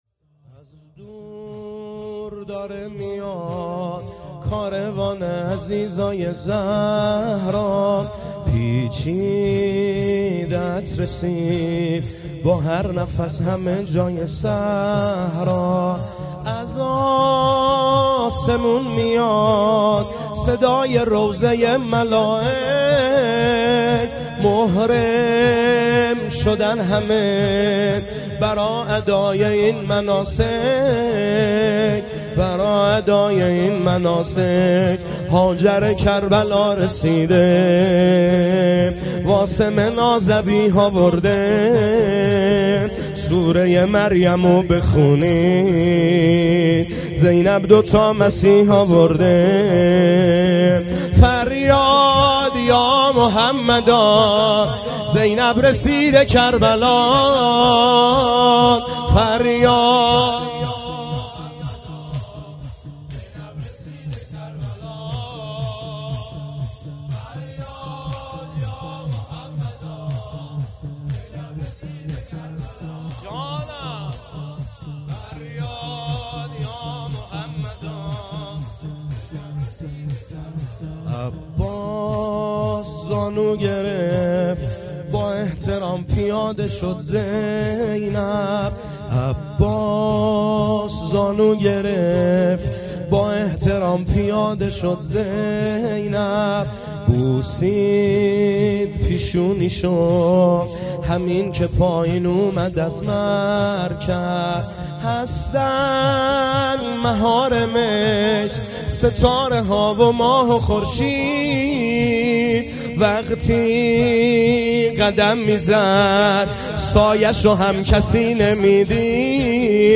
مراســم عـزادارى شـب دوّم محرّم
محرم 98 - شب دوم